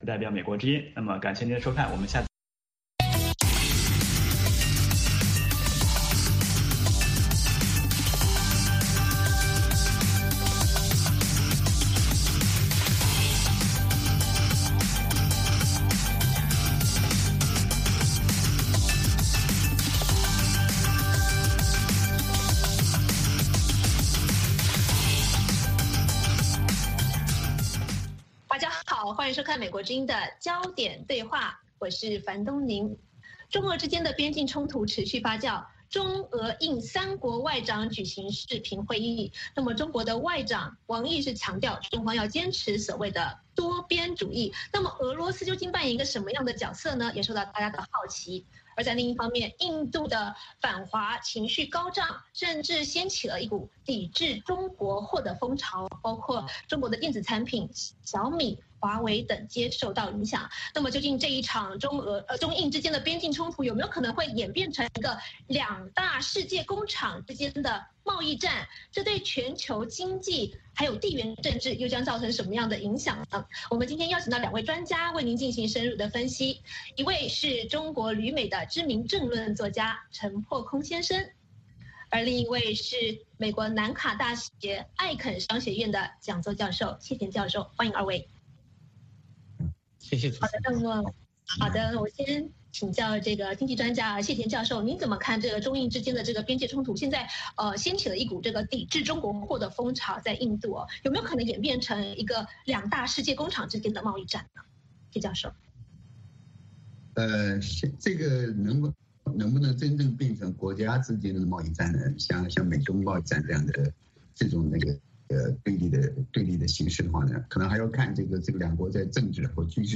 《焦点对话》追踪国际大事、聚焦时事热点。多位嘉宾分析、解读和评论新闻。